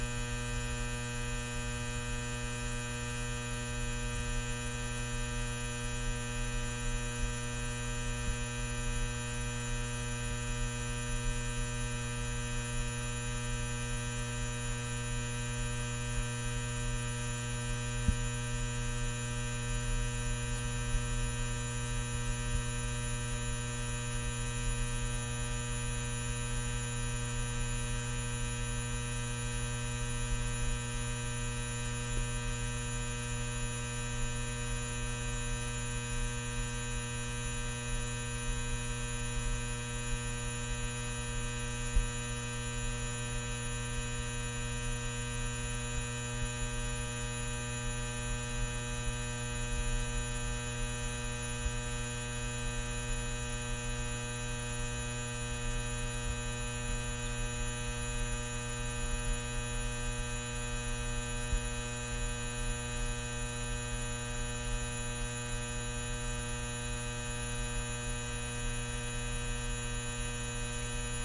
随机" 霓虹灯嗡嗡嗡立体声接近低切的味道4
描述：霓虹灯嗡嗡声嗡嗡声立体声关闭lowcut to taste4.flac
Tag: 关闭 嗡嗡声 低胸 符号 霓虹灯 嗡嗡声 立体声